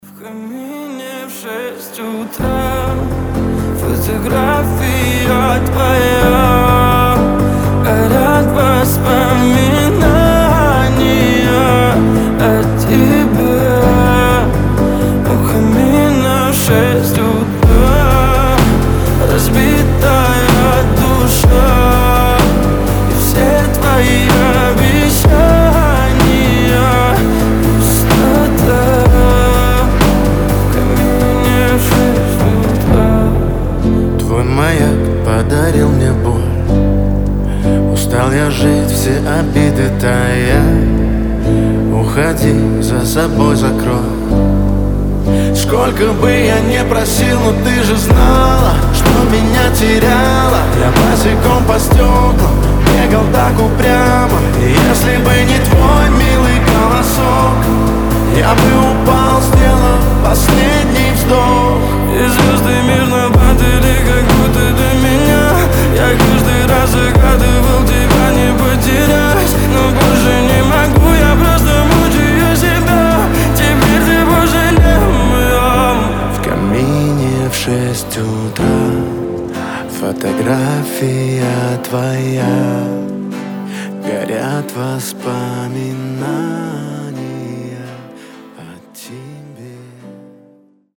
• Качество: 320, Stereo
гитара
лирика
грустные
красивый мужской голос
мелодичные
дуэт
пианино